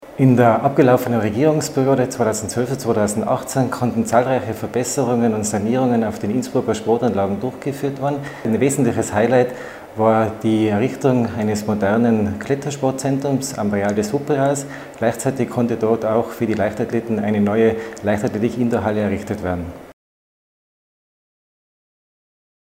OT von Vizebürgermeister Christoph Kaufmann